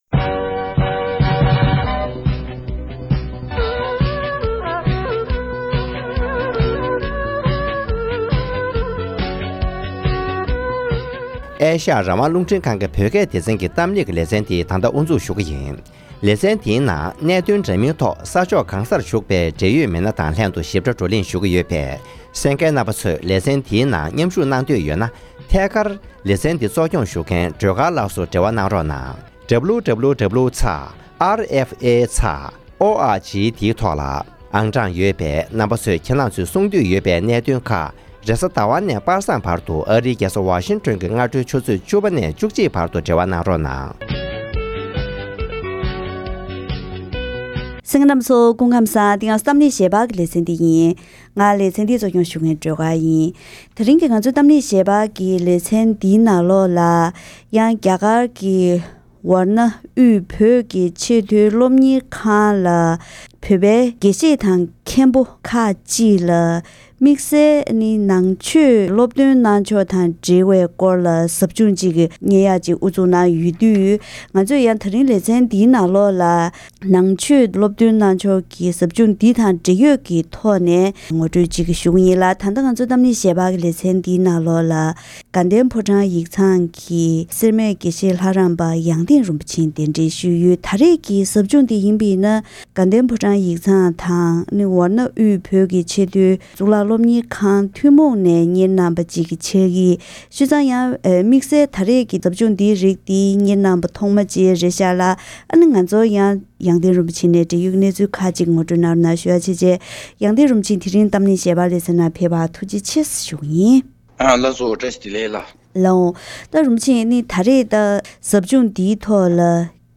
༄༅། །ཐེངས་འདིའི་གཏམ་གླེང་ཞལ་པར་གྱི་ལེ་ཚན་ནང་།